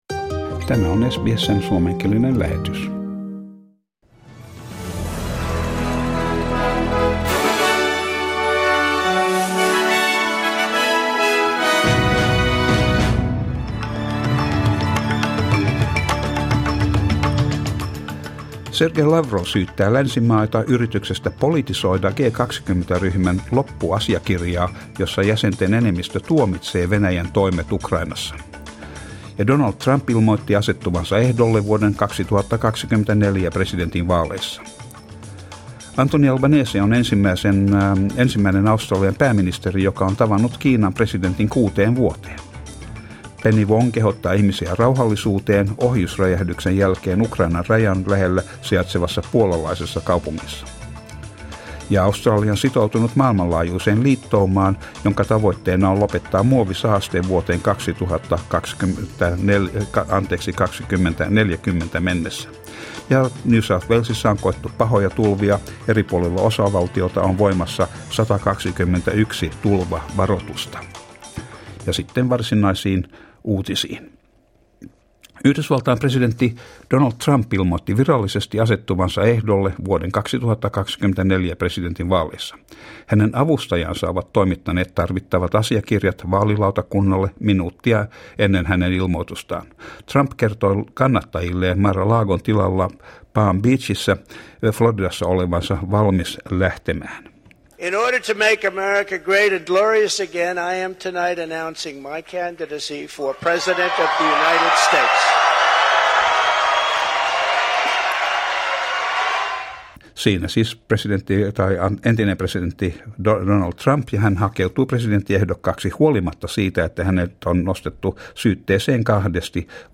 Uutiset ja sää 16.11.22